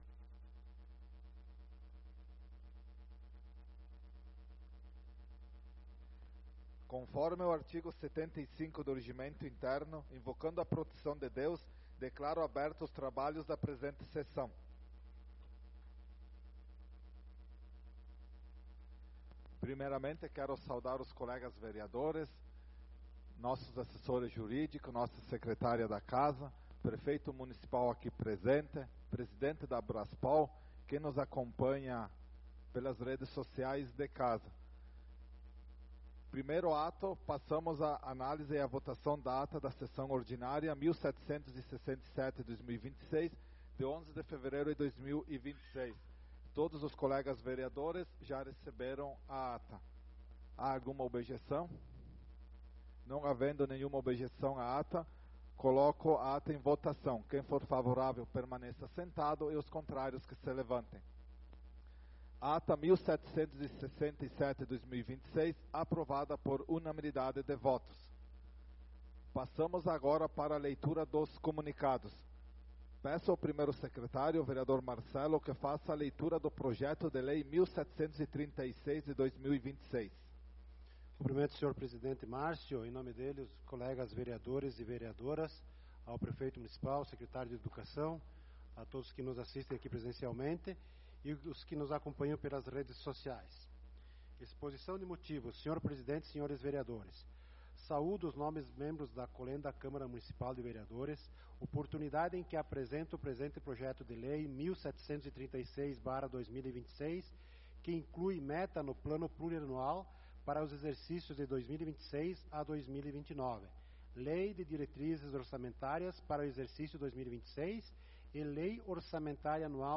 Sessão Ordinária do dia 18/02/2026
Tribuna Livre com o prefeito municipal Roberto Panazzolo